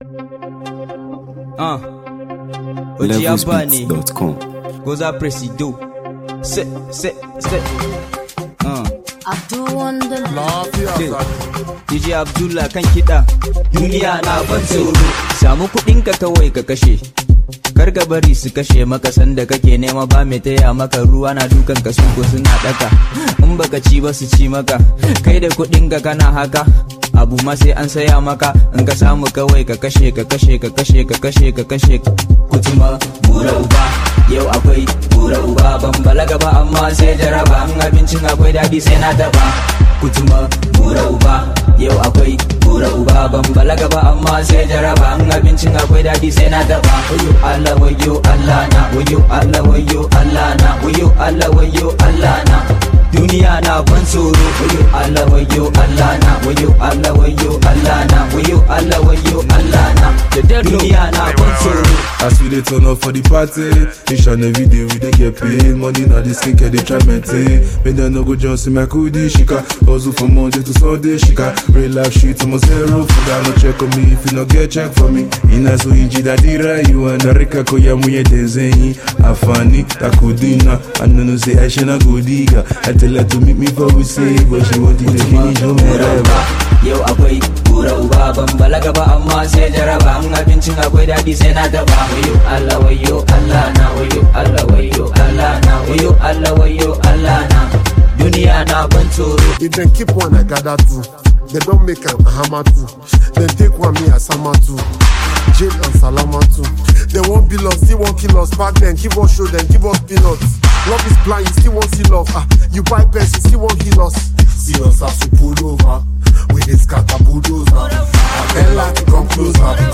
Nigeria Music 2025 2:56
a top-tier rapper